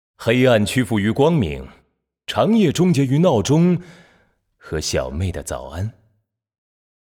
其他语音